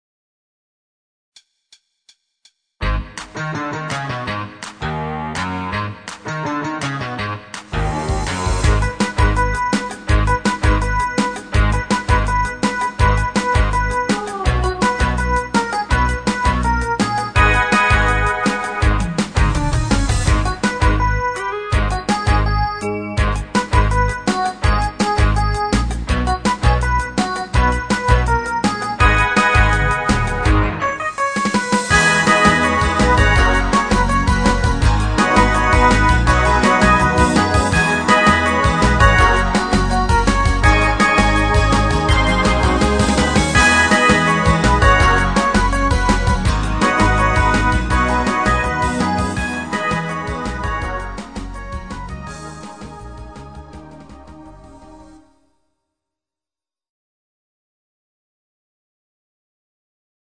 Genre(s): Partyhits  Karneval  |  Rhythmus-Style: Twist